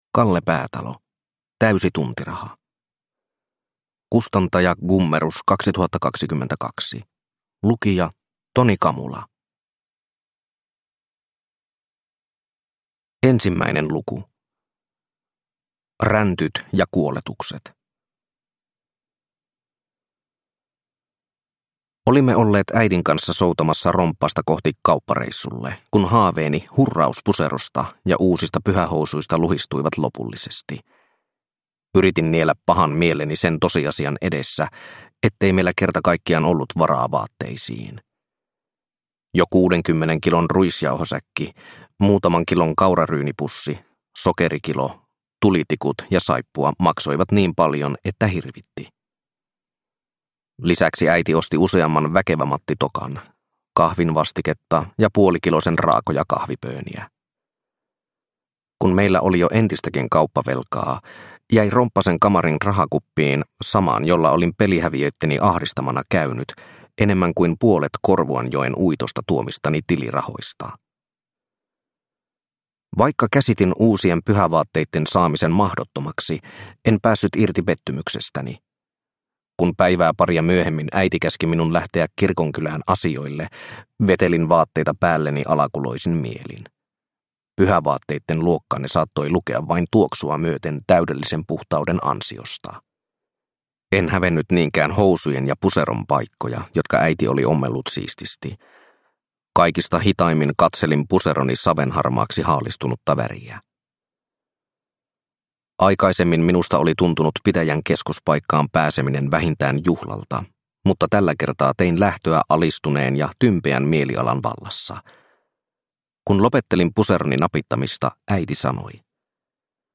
Täysi tuntiraha – Ljudbok – Laddas ner